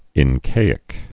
(ĭn-kāĭk)